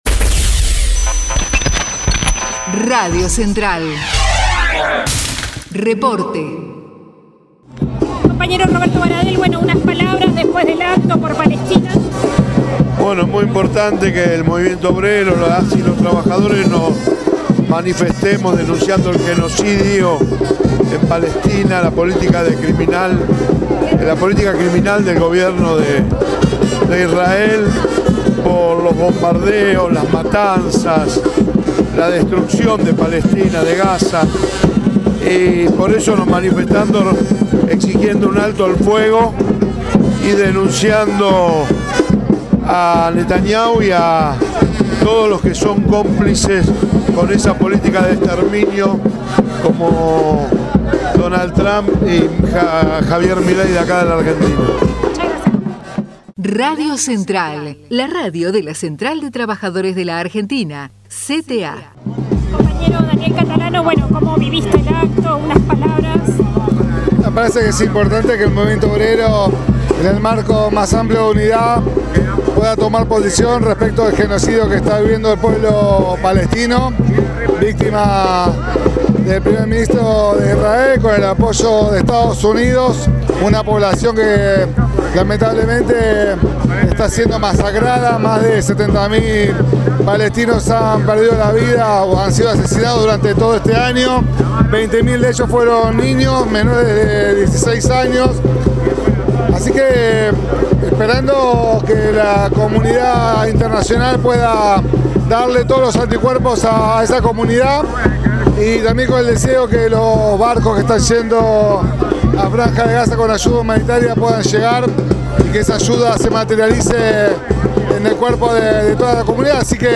ACTO CONTRA EL GENOCIDIO EN PALESTINA - Testimonios CTA
2025_acto_x_palestina.mp3